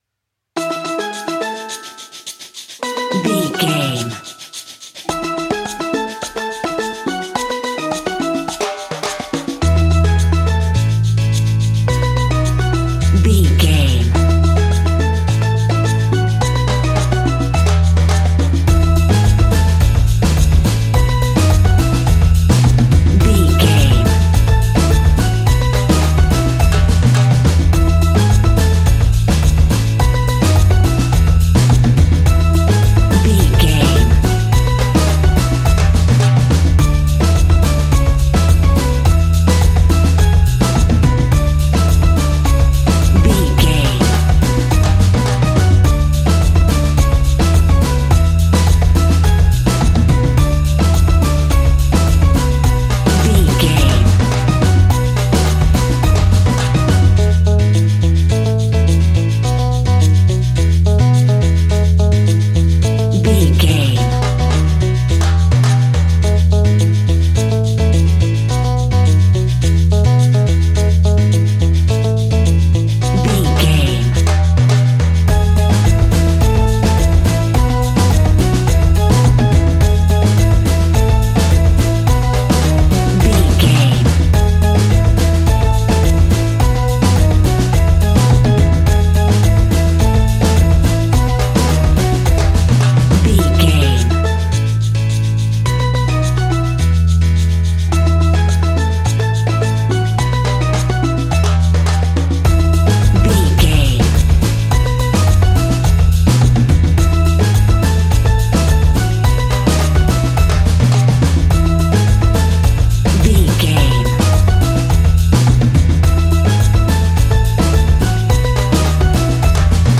Ionian/Major
calypso
steelpan
worldbeat
calpso groove
happy
drums
percussion
bass
brass
guitar